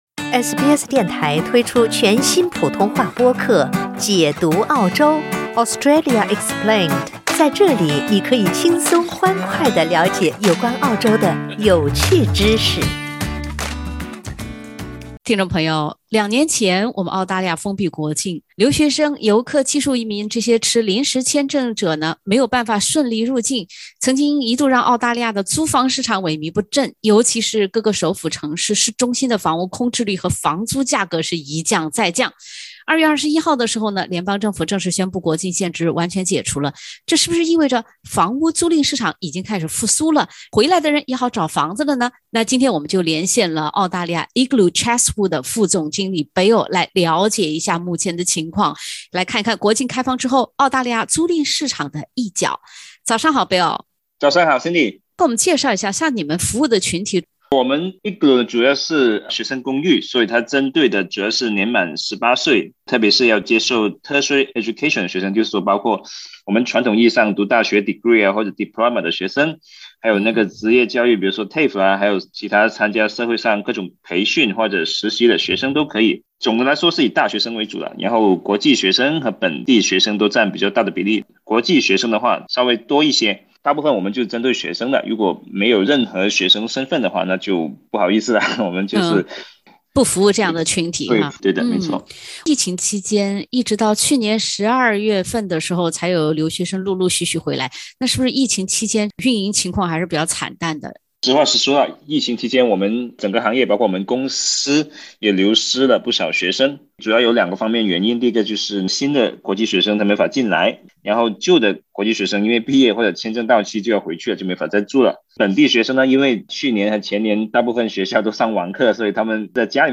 悉尼学生公寓管理人员分享国境开放一月来，悉尼学生公寓出租率与租金变化。（点击封面图片，收听完整对话）